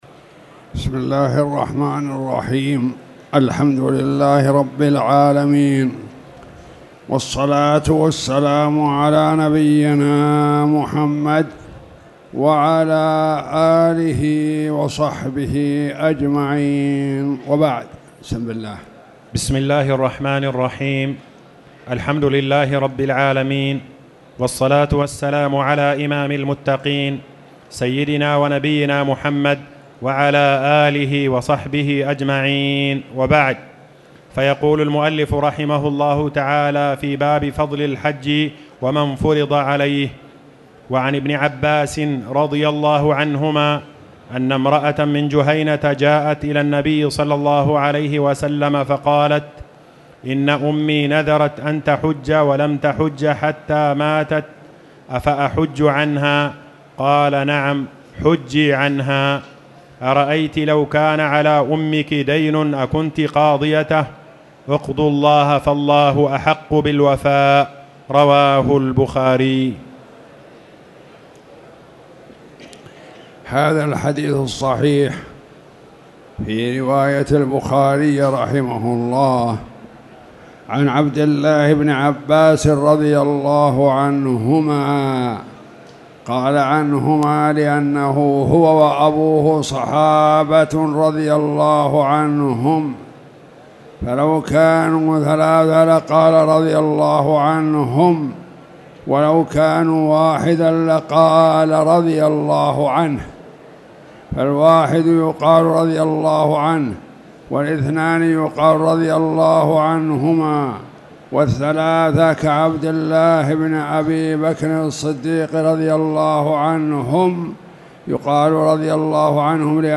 تاريخ النشر ٥ ربيع الأول ١٤٣٨ هـ المكان: المسجد الحرام الشيخ